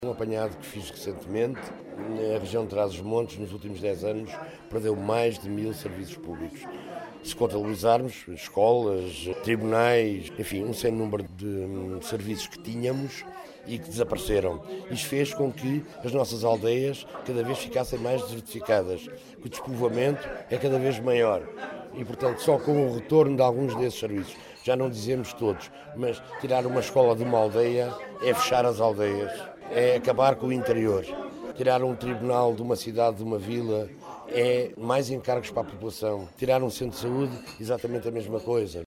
Declarações à margem de um almoço comemorativo do 95º aniversário do partido, este sábado, em Macedo de Cavaleiros.